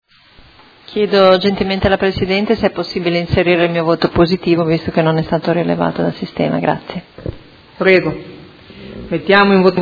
Simona Arletti — Sito Audio Consiglio Comunale
Seduta del 9/11/2017.